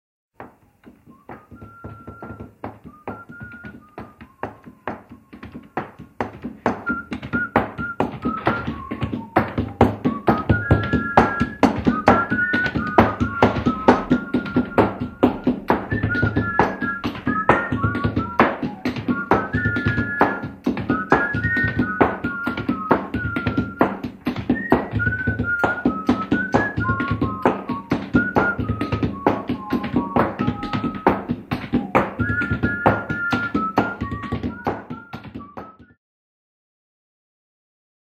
Polinezija megdzioja pauksti (3).mp3